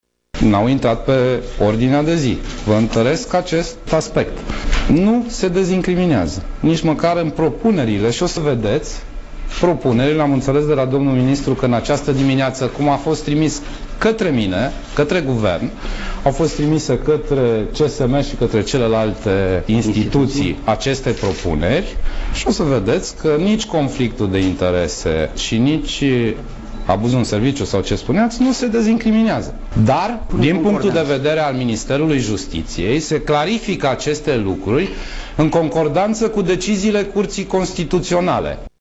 Premierul Sorin Grindeanu a subliniat că în ședința de Guvern de astăzi au fost dezbătute doar punctele precizate pe agenda de lucru, care este publicată pe site-ul executivului.